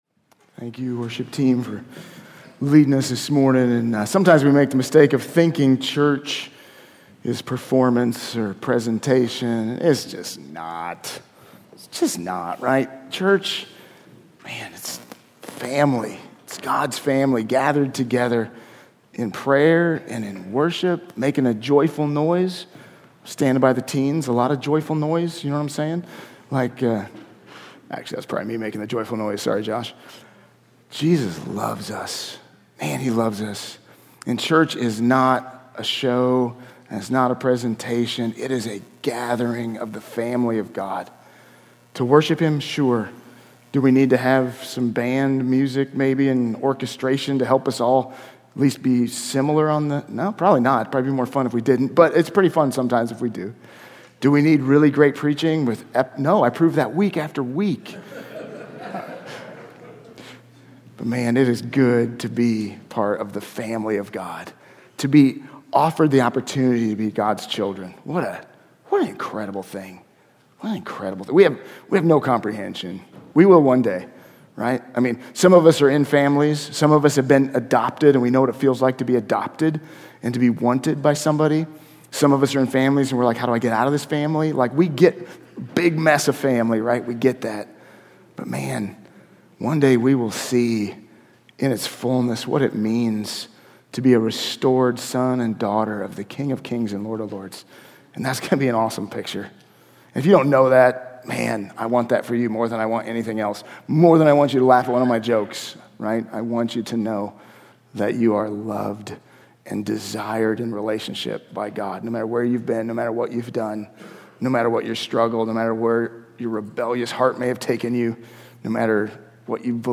Sermons | White Rock Fellowship